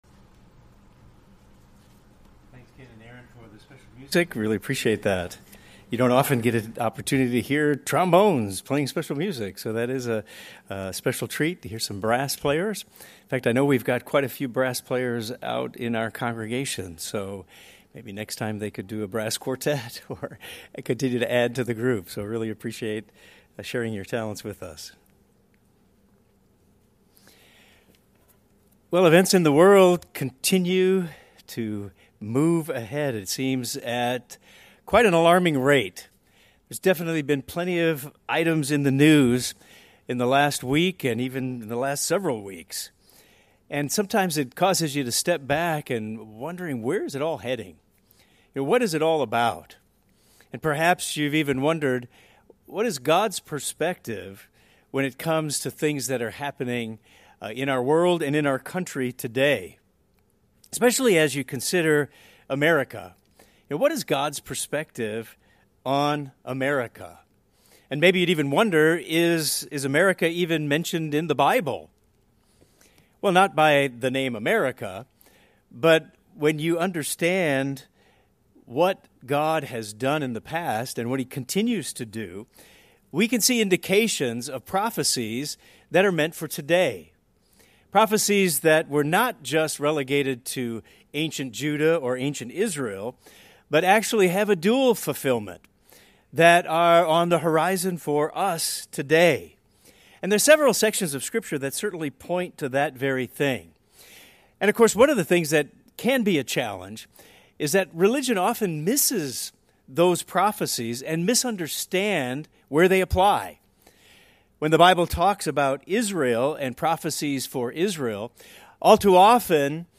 What does the Bible have to say about America and what lies ahead? This sermon will discuss specific prophecies that were not fulfilled completely in the past and apply as prophecy for today.